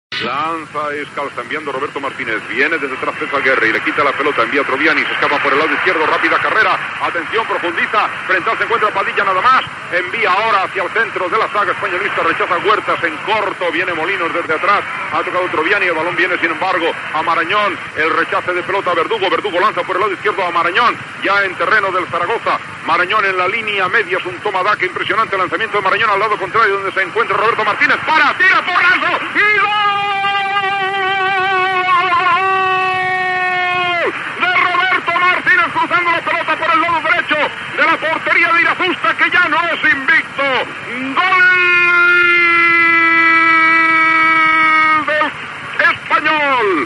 Narració del gol de l'Espanyol.
Esportiu